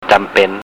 Jam (eher wie Cham gesprochen) Pen